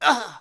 damage_3.wav